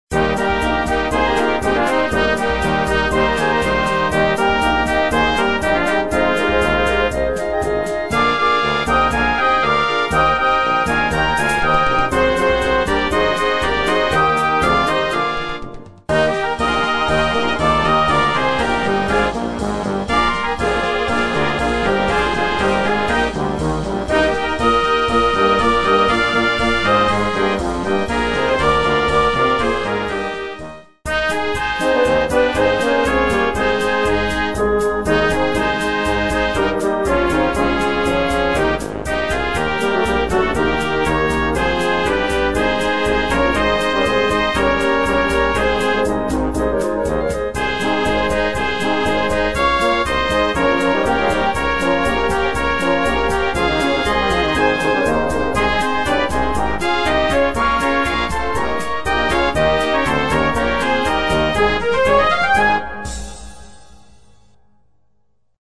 Blasmusik: